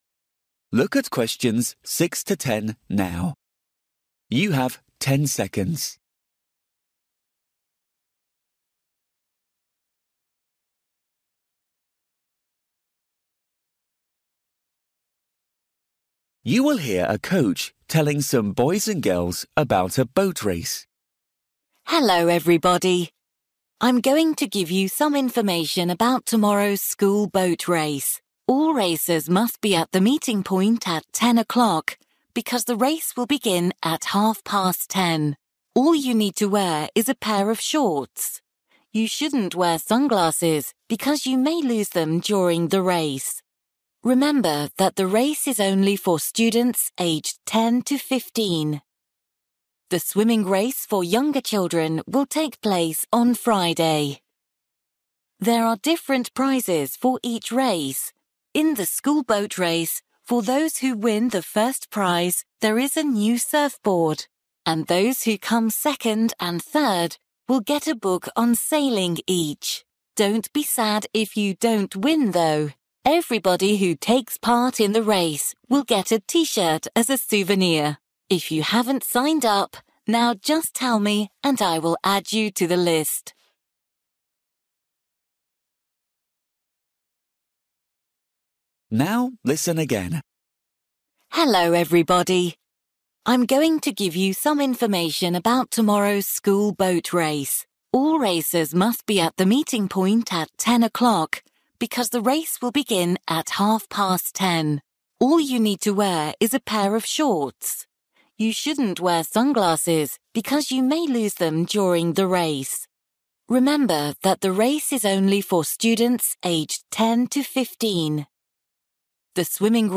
You will hear a coach telling some boys and girls about a boat race.